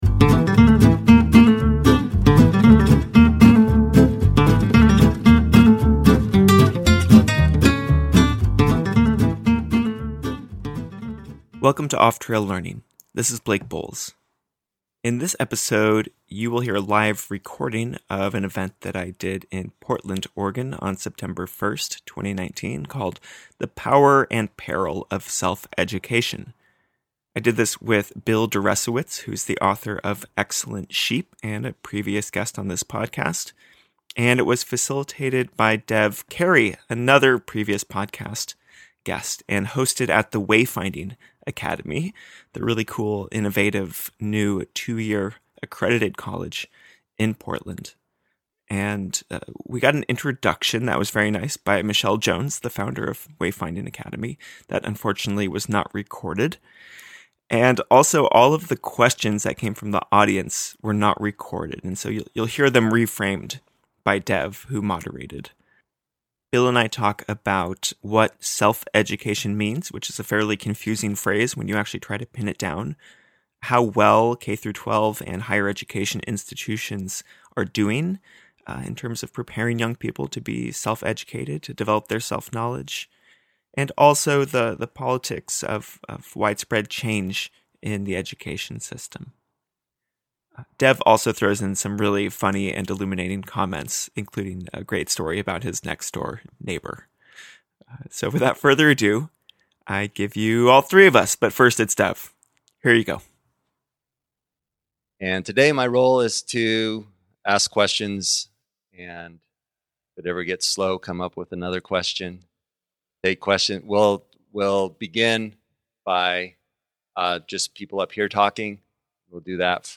This event was recorded live in Portland, Oregon, on September 1, 2019, at the Wayfinding Academy.